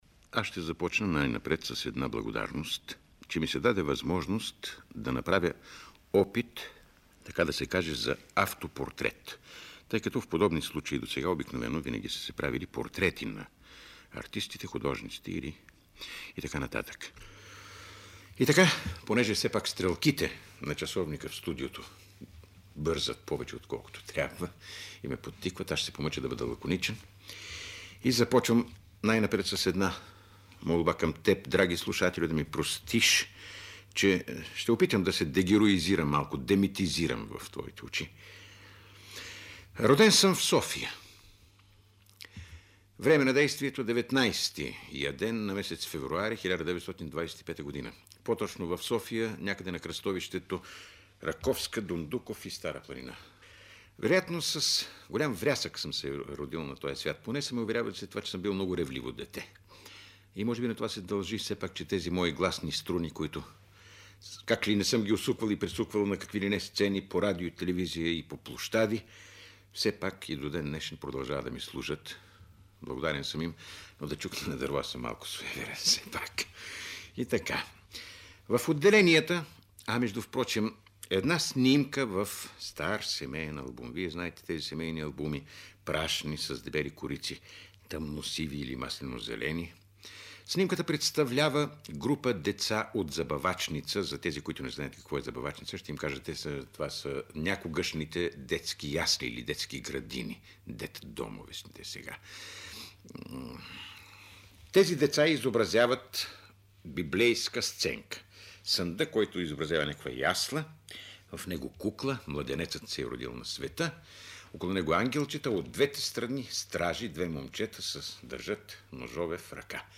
Роден е в София, а за ранните си години и пътищата, довели го до микрофона на Българското радио, разказва – увлекателно и с чувство за хумор – в запис по повод неговата 50-годишнина през 1975 година: